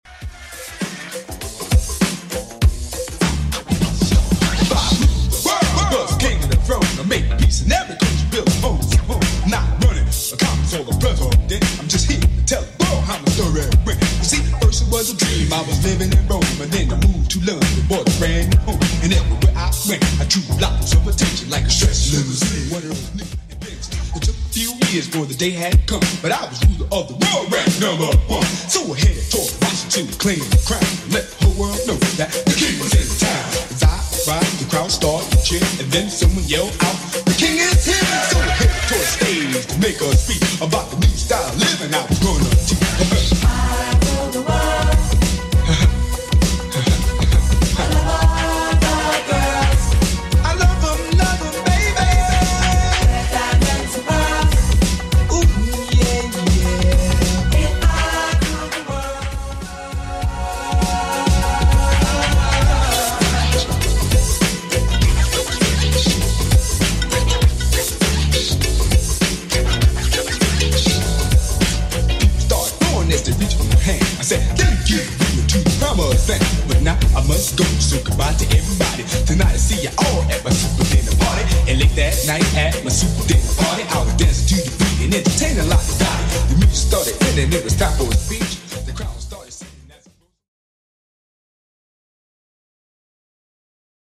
Genre: 80's Version: Clean BPM: 115